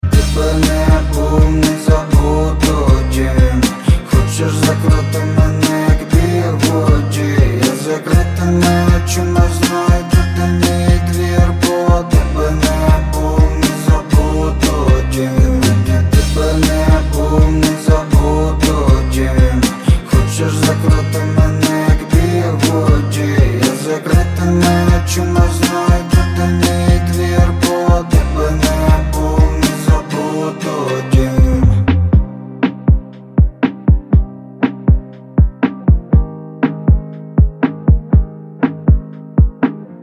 Архив Рингтонов, Рэп рингтоны